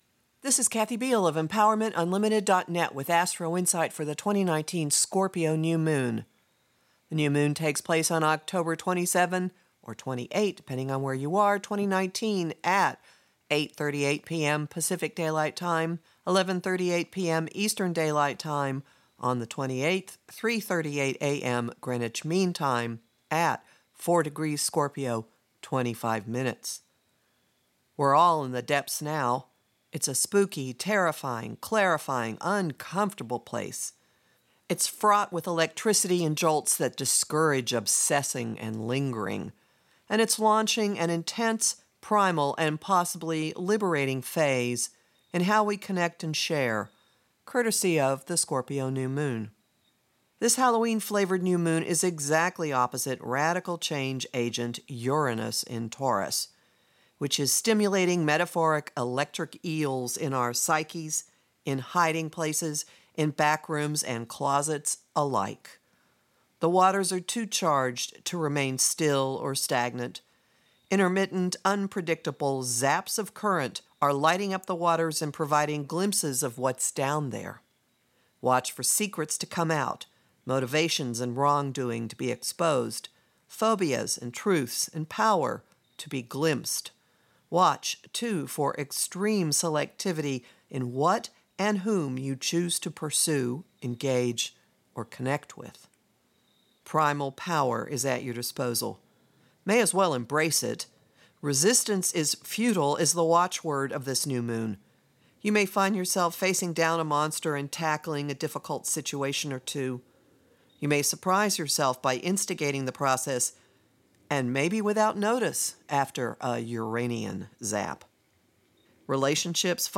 To listen to Astrologer